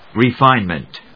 音節re・fíne・ment 発音記号・読み方
/‐mənt(米国英語)/